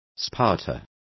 Complete with pronunciation of the translation of spotters.